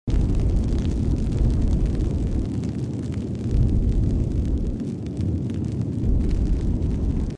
object_on_fire01.wav